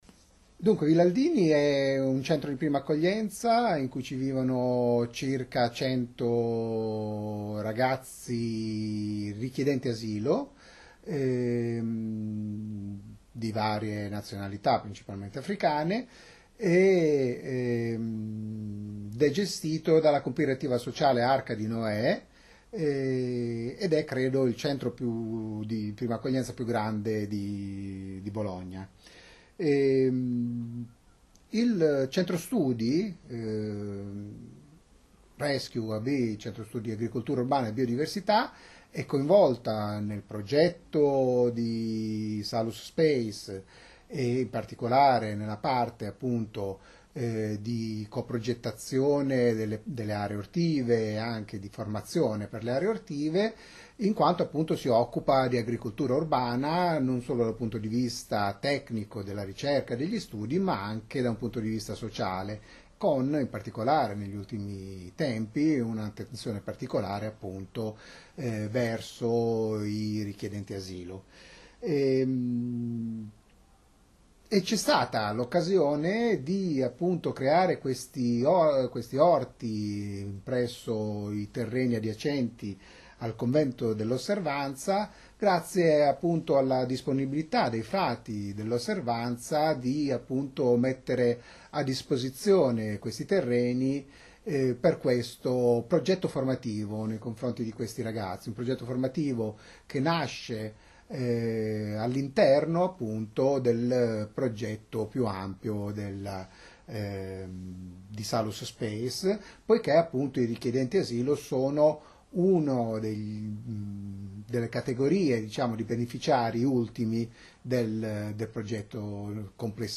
Questa la prima parte dell’intervista